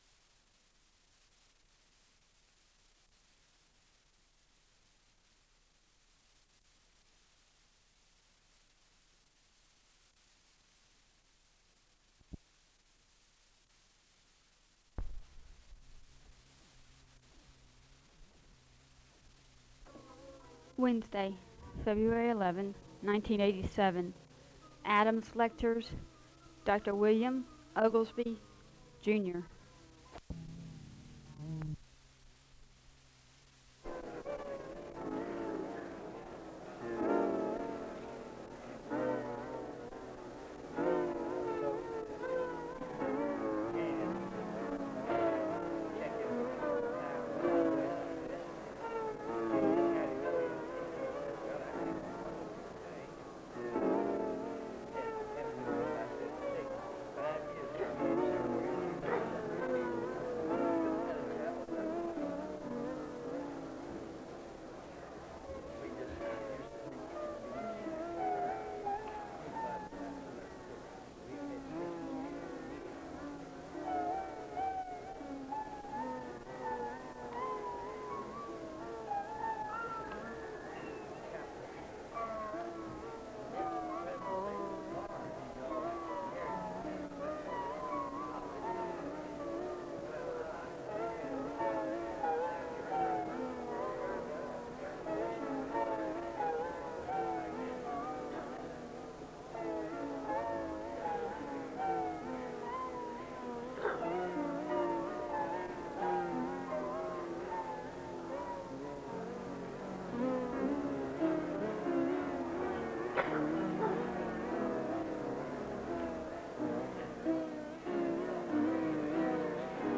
The service begins with organ music (0:00-3:29). There is a Scripture reading from Ephesians 2 (3:30-5:31).
The choir sings an anthem (6:33-11:55).
The service concludes with a benediction from Scripture (51:31-52:04).